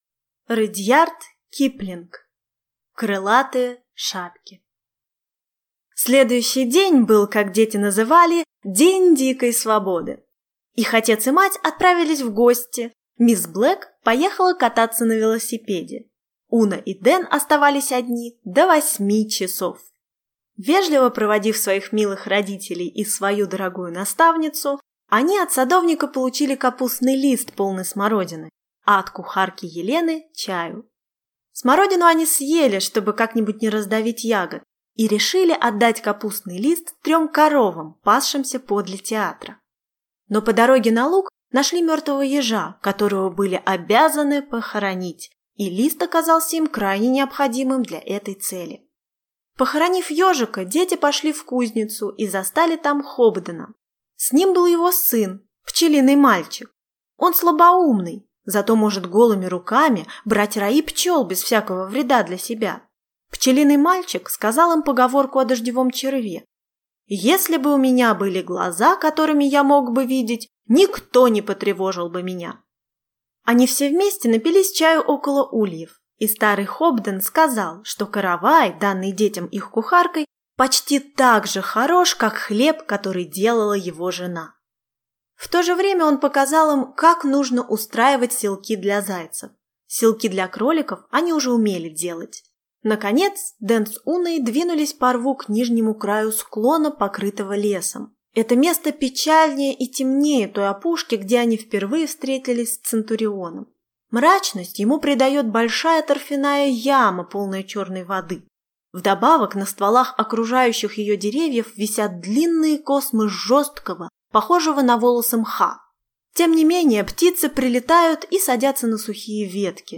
Аудиокнига Крылатые шапки | Библиотека аудиокниг
Прослушать и бесплатно скачать фрагмент аудиокниги